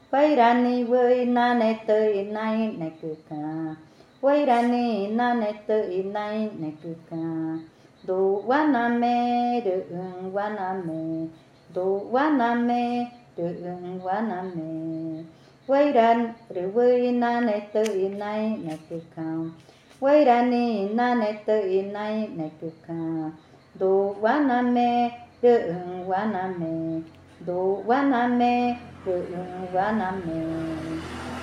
Canción infantil 13. Canción del huasaí
Cushillococha